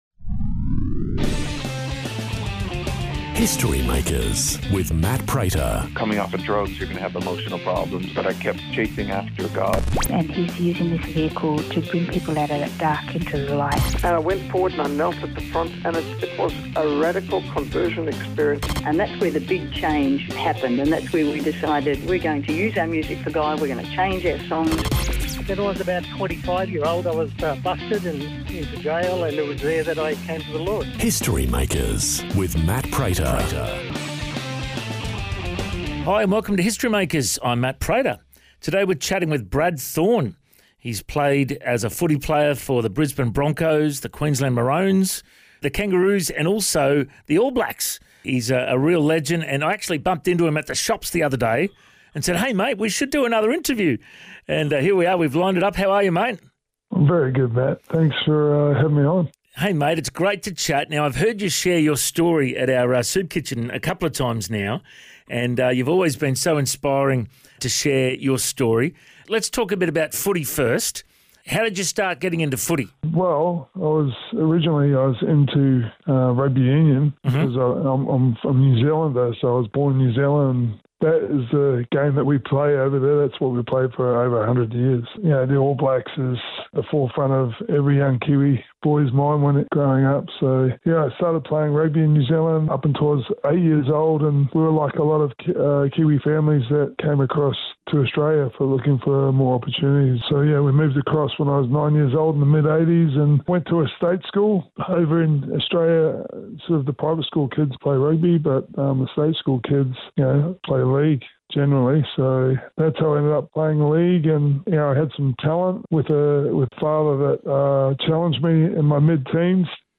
In this interview, Brad Shares about his faith journey and what inspired his new book, “Champions do extra.”